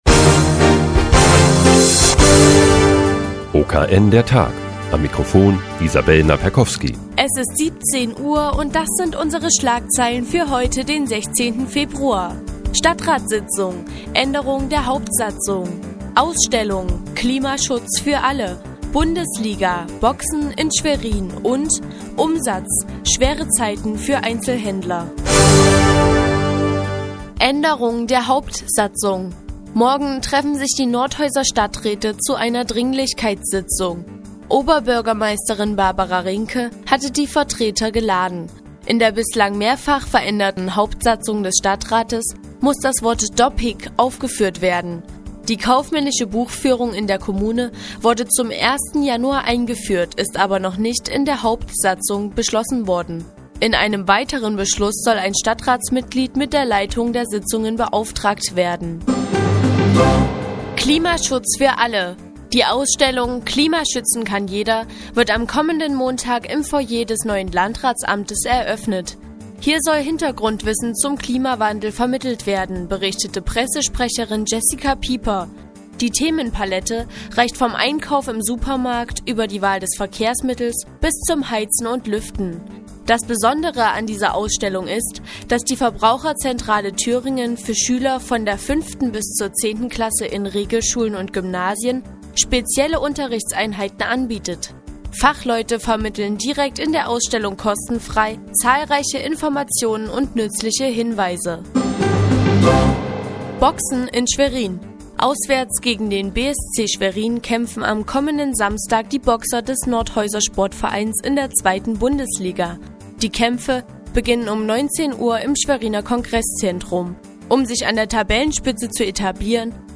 Die tägliche Nachrichtensendung des OKN ist nun auch in der nnz zu hören. Heute geht es um eine Ausstellung zum Thema Klimaschutz und einen Boxwettkampf des Nordhäuser Sportvereins in Schwerin.